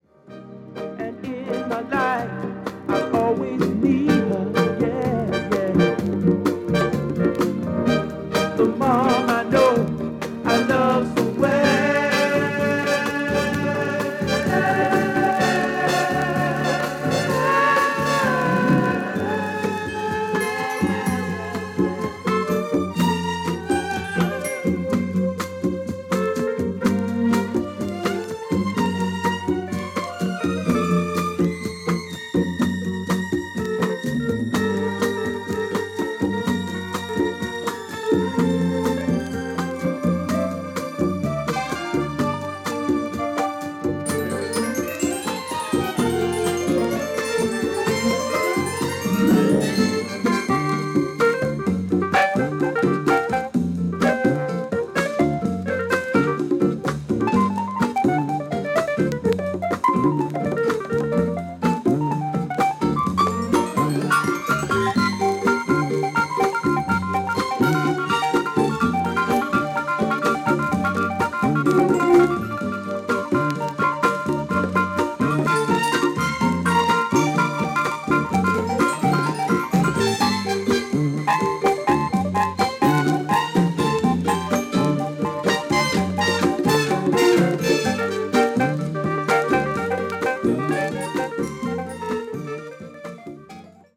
初期作品はDEEP FUNK作品が多いですね。